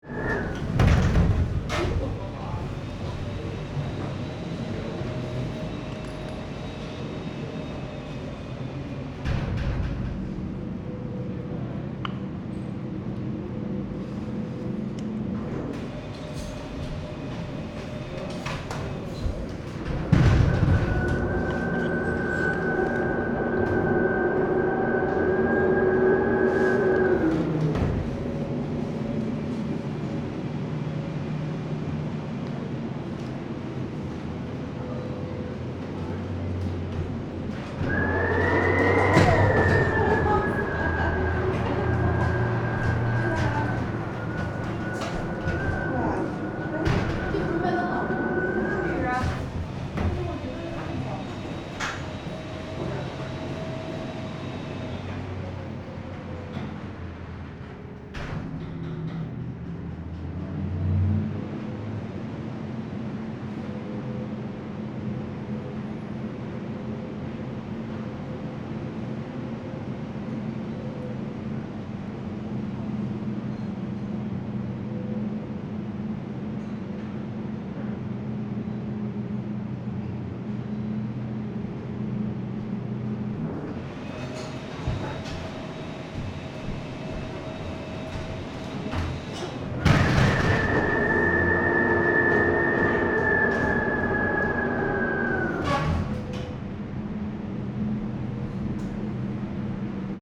Sound recorded in Beijing, Shanghai, Hong Kong and Macao.
- A cafe in the Olympic Village, Bejing: Wind enters as doors open and close (1:40)
doors_open_and_close_beijing.mp3